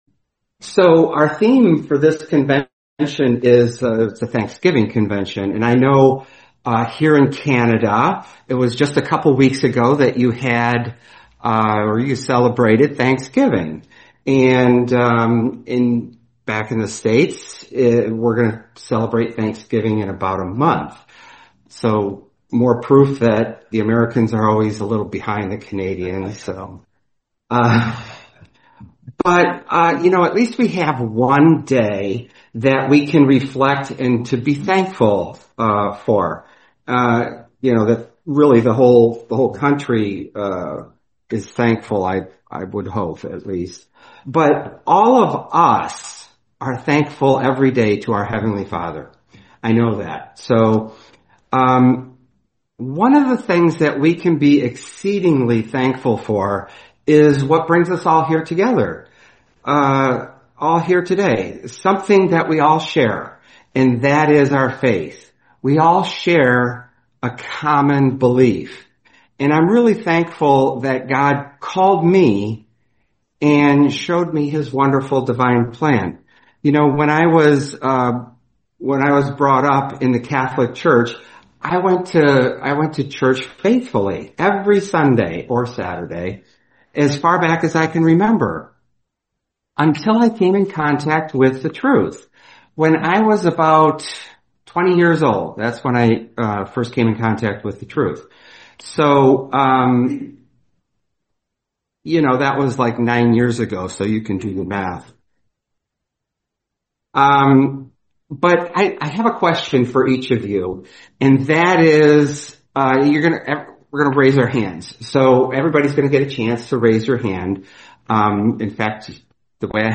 Series: 2025 Toronto Convention
Long Summary Detailed Summary of the Discourse on Faith, Thanksgiving, and the Kingdom Theme and Context: – The discourse is delivered at a Thanksgiving convention, highlighting the significance of gratitude to God for faith and the divine plan.
– Audience participation is encouraged by raising hands to identify who did or did not grow up “in the truth,” showing that many came to faith later in life.